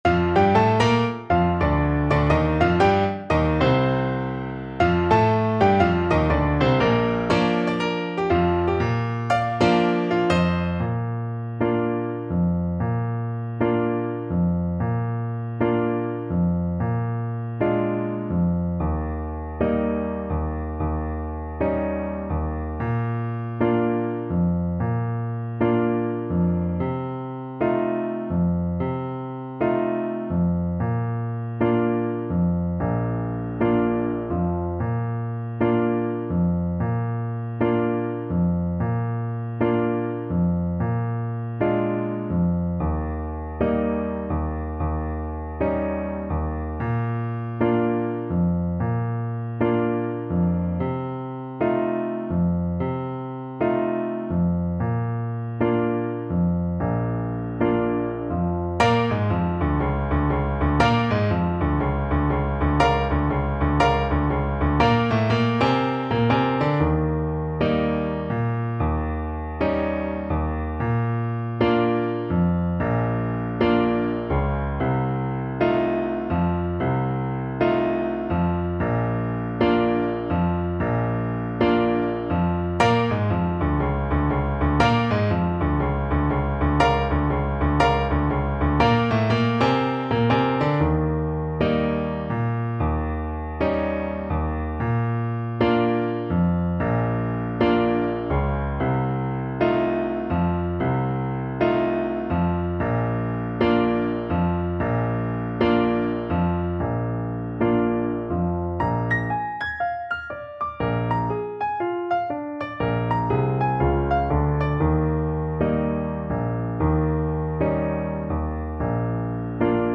4/4 (View more 4/4 Music)
Moderato = 120
Jazz (View more Jazz Saxophone Music)